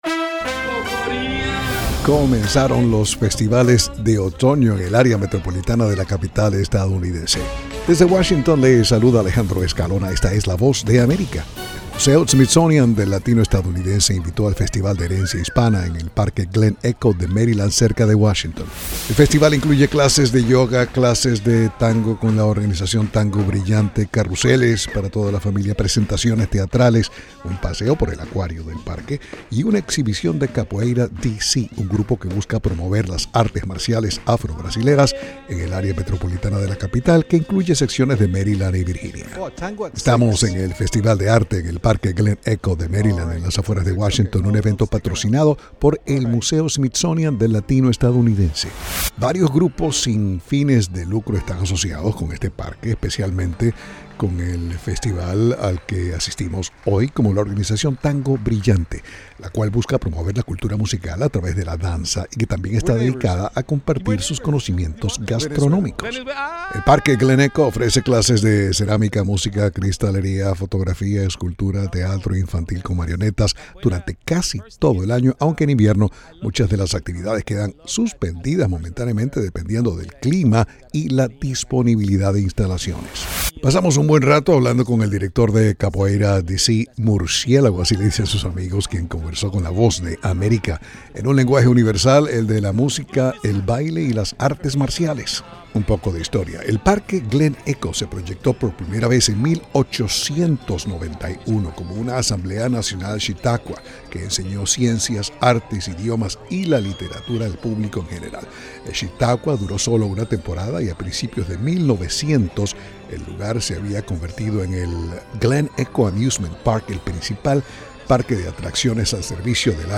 noticias del espectáculo